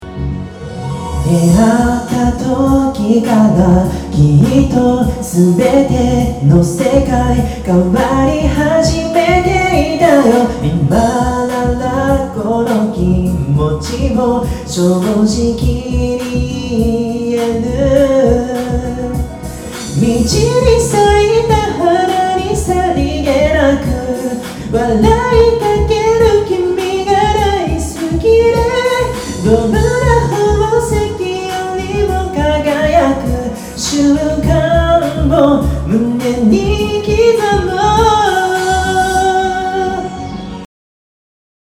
僕の歌声（現在）